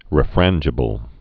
(rĭ-frănjə-bəl)